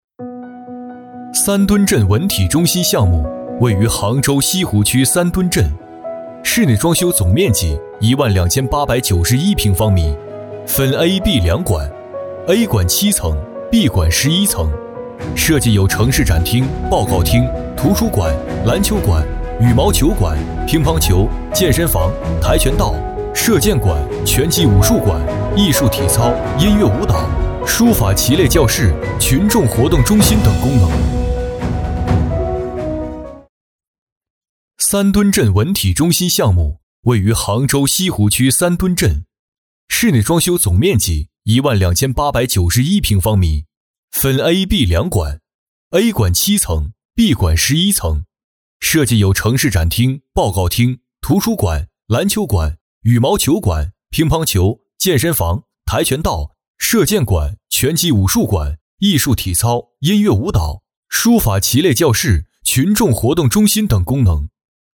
三墩镇文体中心（项目汇报）